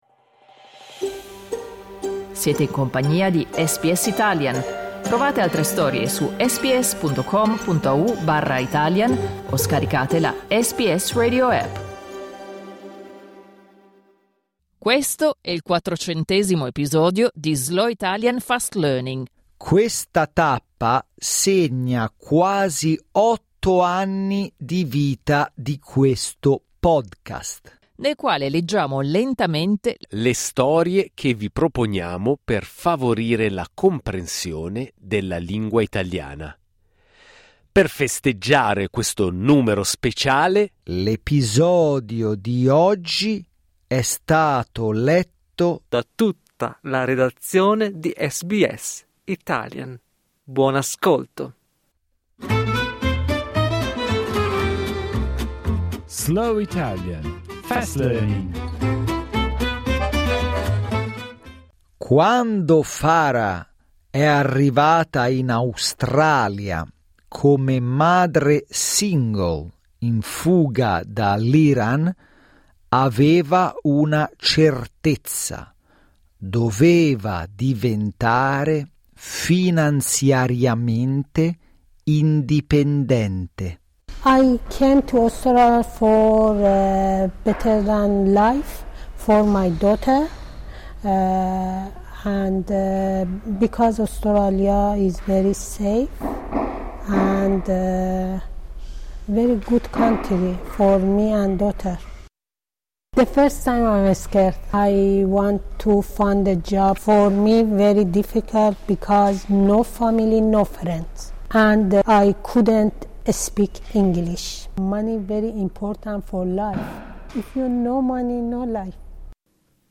This milestone marks almost eight years of this podcast, in which we read stories slowly to help you understand the Italian language. To celebrate this special milestone, today's episode has been read by the entire SBS Italian editorial team.